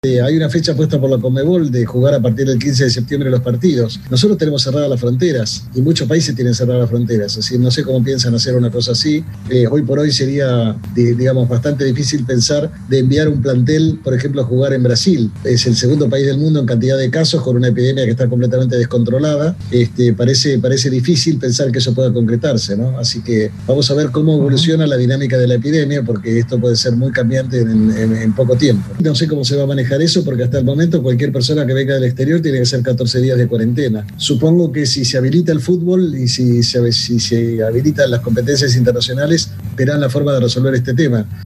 (Pedro Cahn, asesor de la presidencia de Argentina)